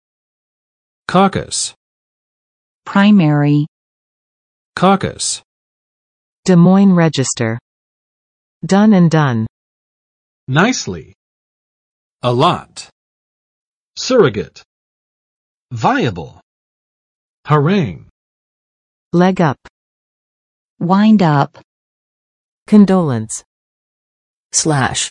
[ˋkɔkəs] n.（政党等的）核心小组；政党地方委员会